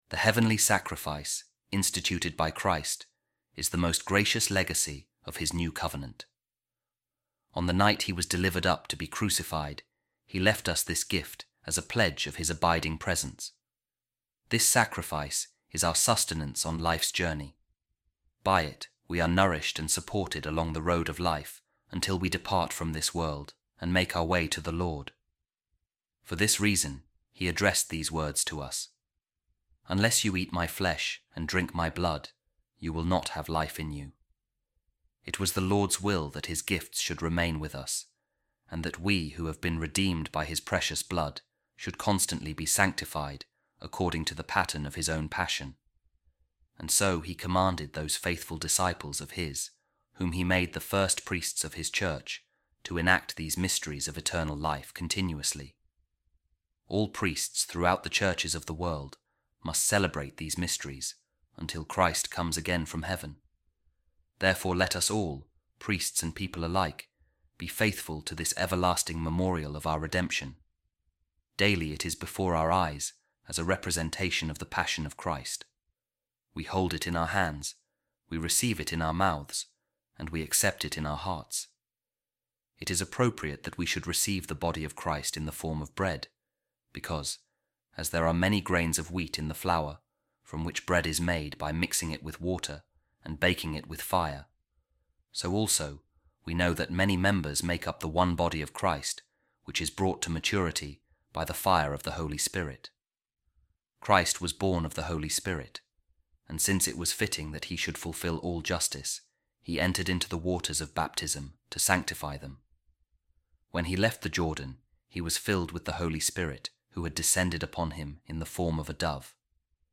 Office Of Readings | Eastertide Week 2, Thursday | A Reading From A Treatise Of Saint Gaudentius Of Brescia | Jesus Our Bequest Of The New Testament